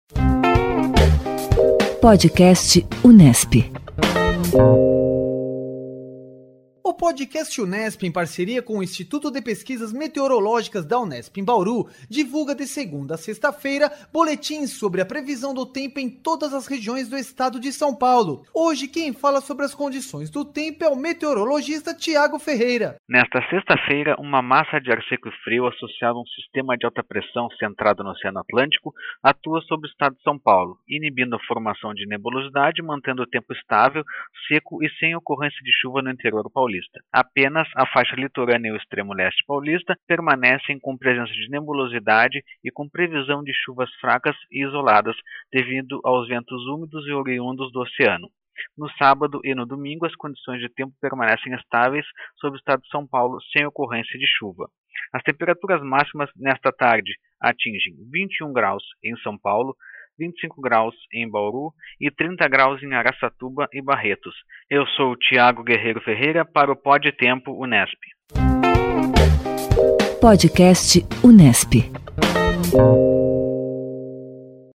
O Podcast Unesp, em parceria com o Instituto de Pesquisas Meteorológicas da Unesp, divulga diariamente boletins sobre a previsão do tempo em todas as regiões do Estado de São Paulo.